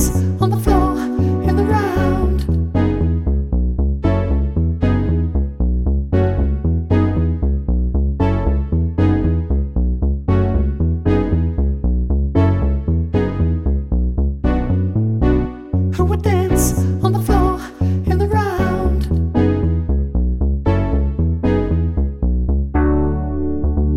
No Drums Or Percussion Pop (1980s) 5:01 Buy £1.50